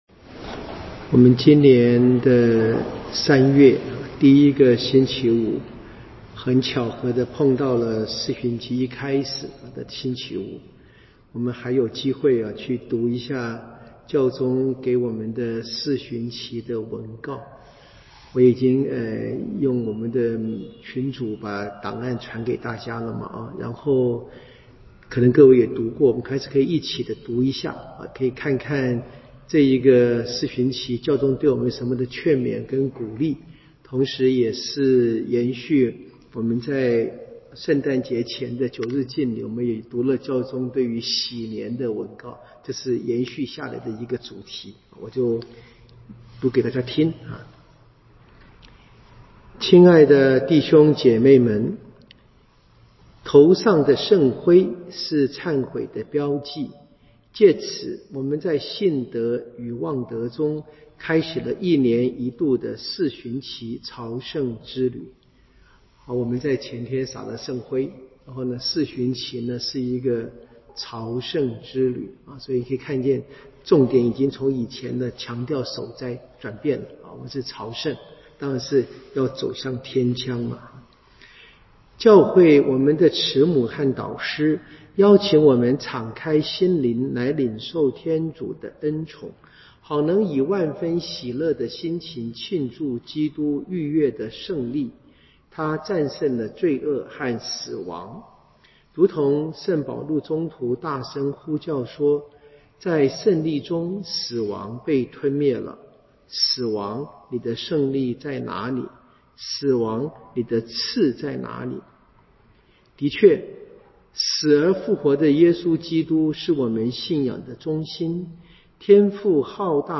彌撒講道與聖經課程